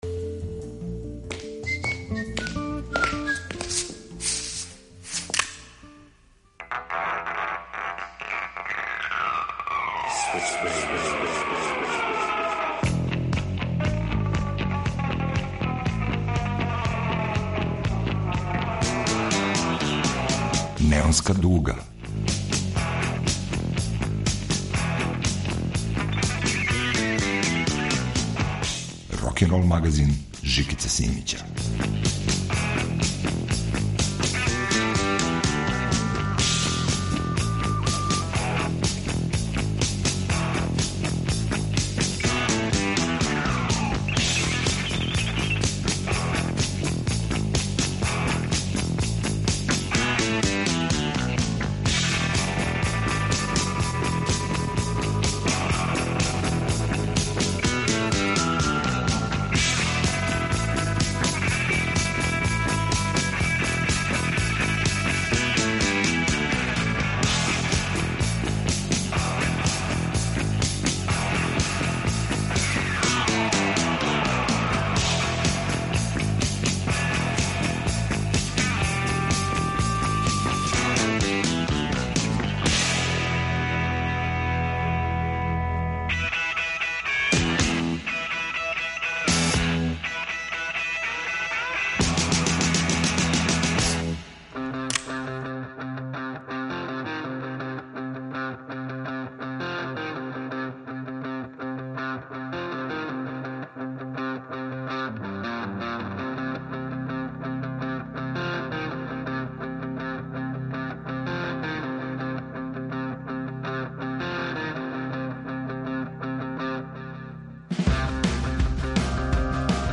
Од блуза до врхунске поп музике.